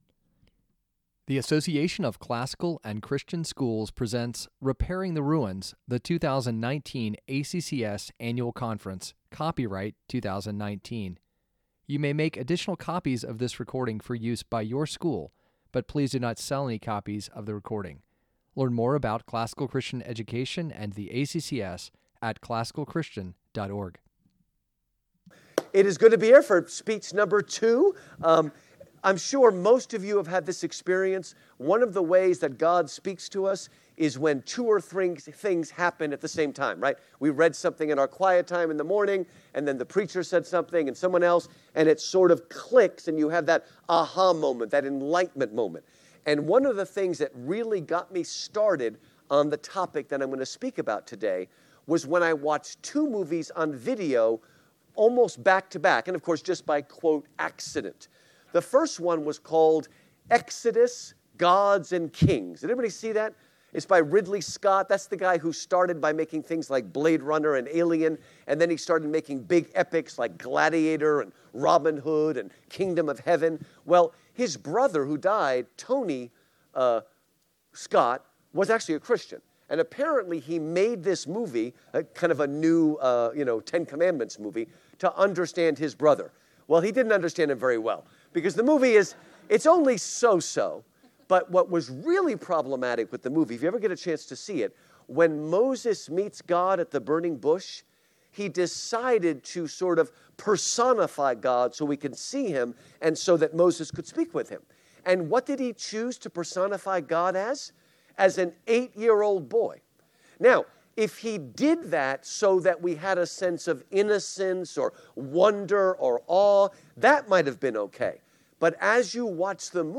2019 Workshop Talk | 57:02 | All Grade Levels, Culture & Faith, History